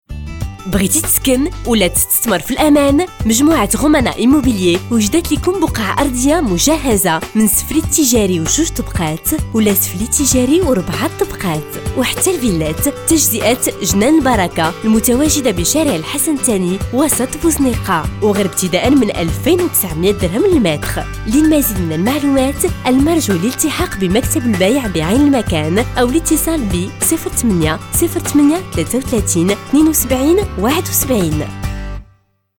Notre spot publicitaire Radio